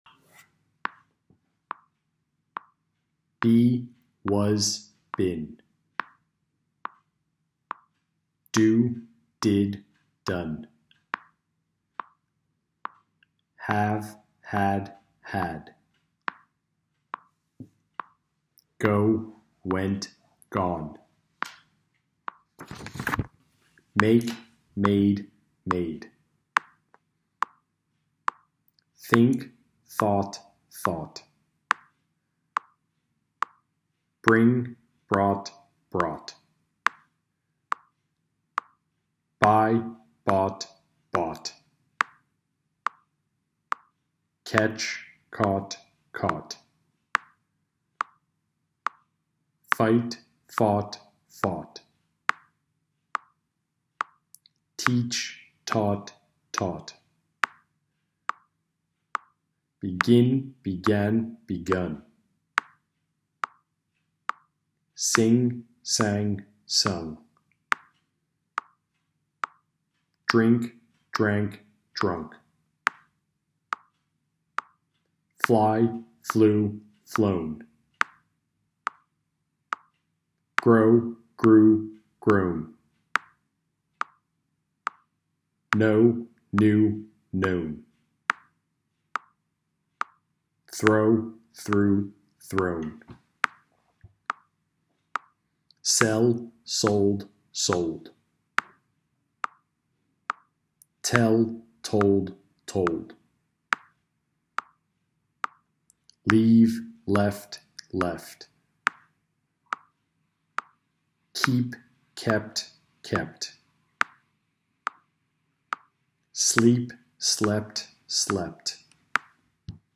Irregular Verbs by Sound Group – English Alive Donostia
Irregular-Verbs-by-Sound-Group-English-Alive-Donostia.m4a